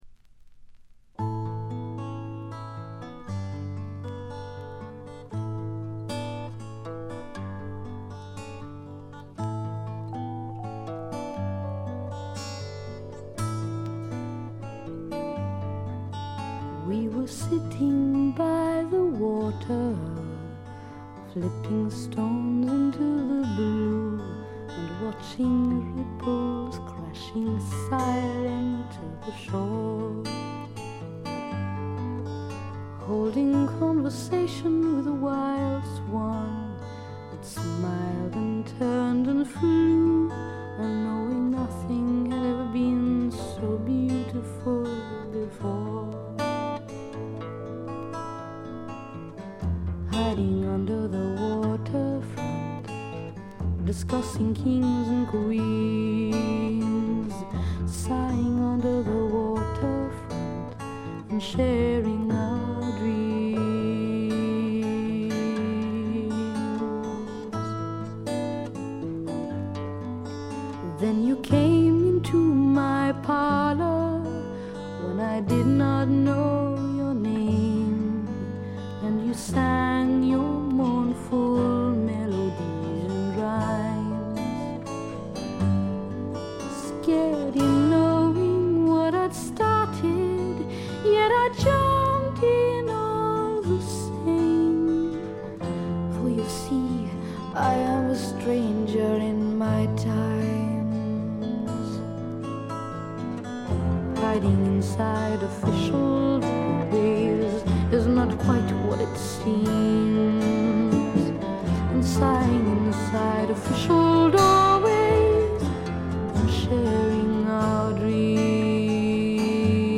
静音部でバックグラウンドノイズ、チリプチ少々、散発的なプツ音少し。
ほとんど弾き語りのような曲が多いのもよいですね。
試聴曲は現品からの取り込み音源です。